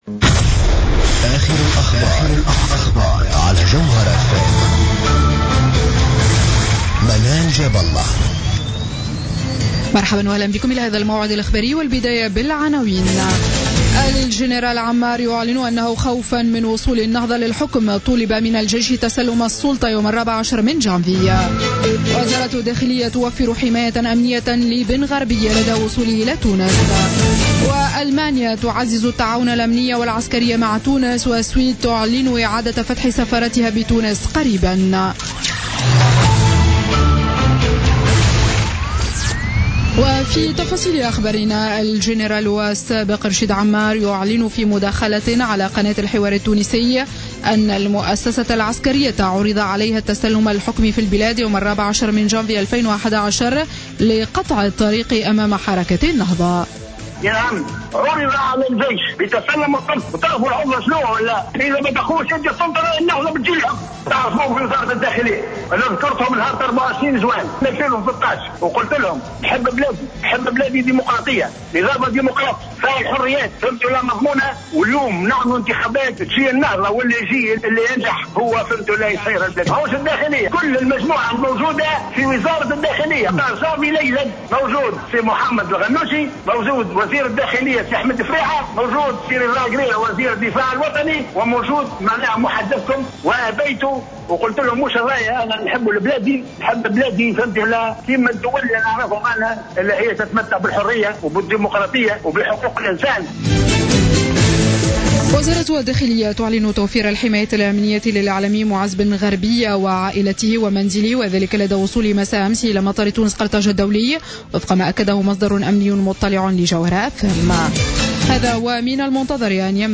نشرة أخبار منتصف الليل ليوم الجمعة 6 نوفمبر 2015